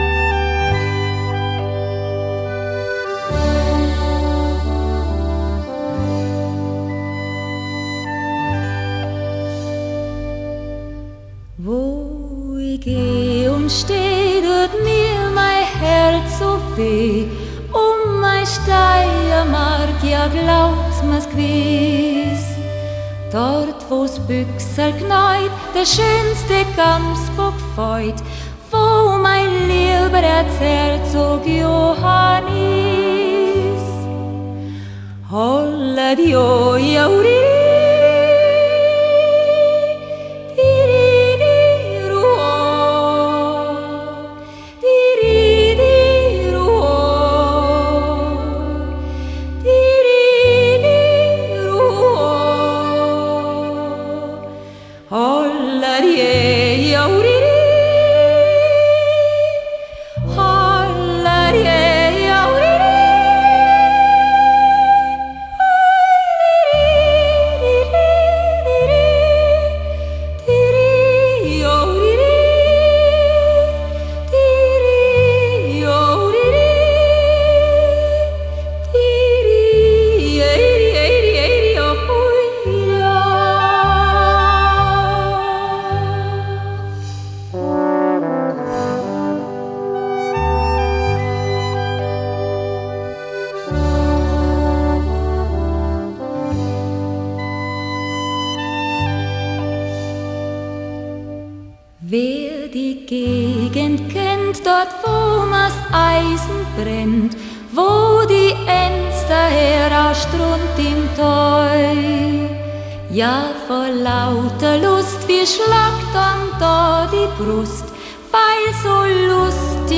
Genre: Folk / Country / Retro / Yodel